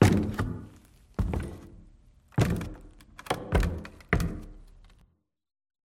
amb_fs_stumble_wood_15.mp3